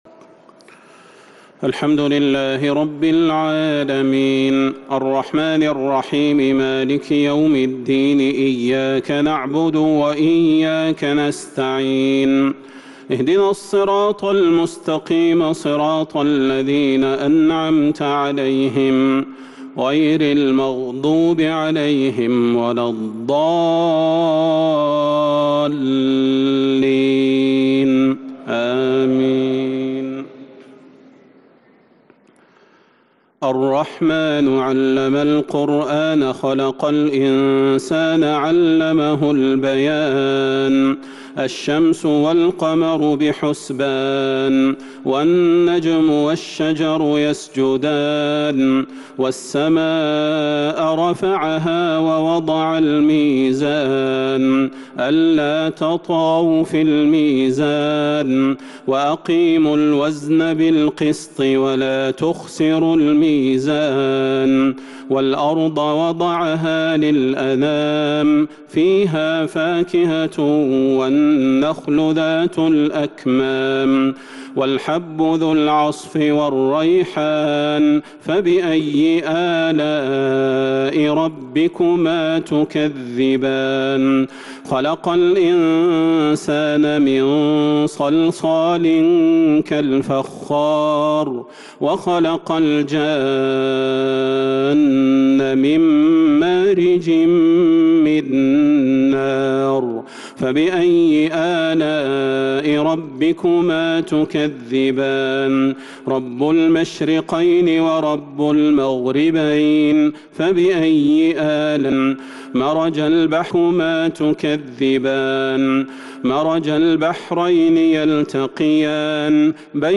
تهجد ليلة 27 رمضان 1443هـ من سور الرحمن و الواقعة و الحديد (1-17) Tahajud 27 st night Ramadan 1443H from Surah Ar-Rahmaan and Al-Waaqia and Al-Hadid > تراويح الحرم النبوي عام 1443 🕌 > التراويح - تلاوات الحرمين